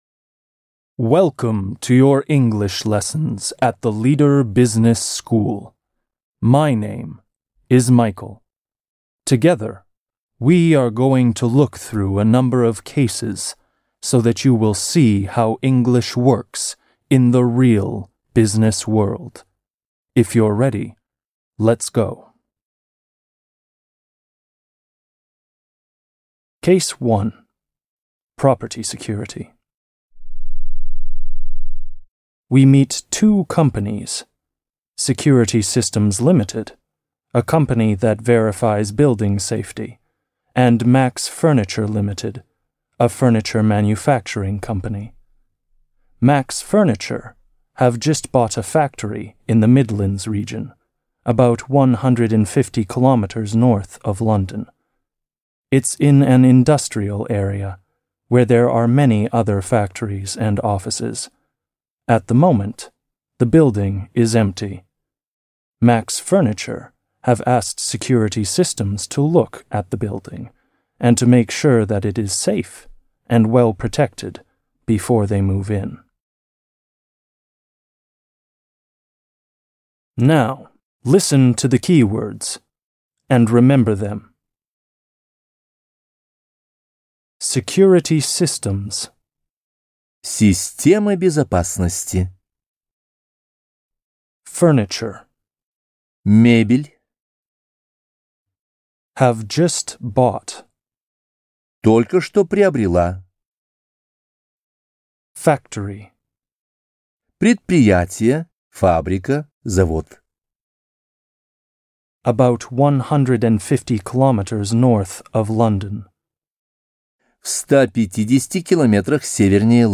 Аудиокнига Let's Speak English. Case 1. Property Security | Библиотека аудиокниг